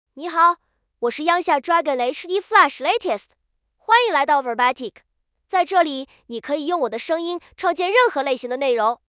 Yunxia Dragon HDFlash LatestMale Chinese AI voice
Yunxia Dragon HDFlash Latest is a male AI voice for Chinese (Mandarin, Simplified).
Voice sample
Listen to Yunxia Dragon HDFlash Latest's male Chinese voice.